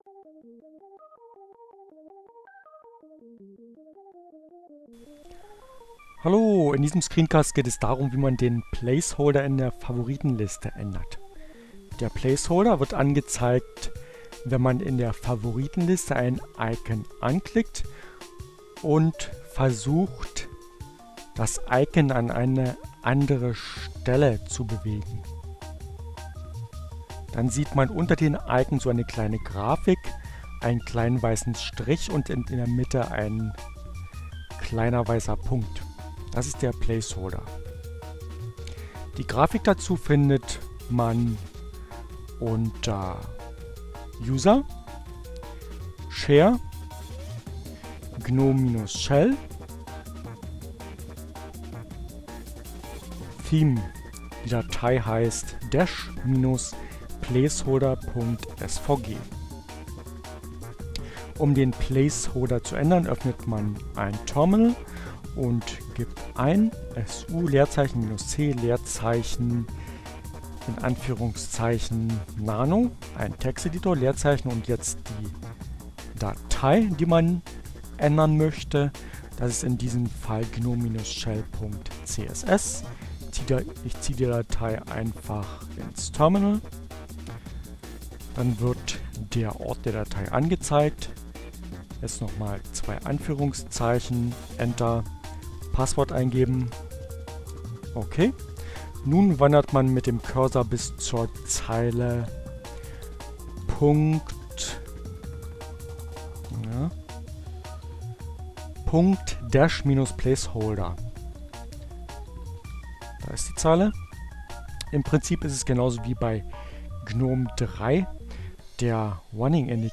Tags: CC by-sa, Fedora, Gnome, Linux, Neueinsteiger, Ogg Theora, ohne Musik, screencast, gnome3, dash, dash-placeholder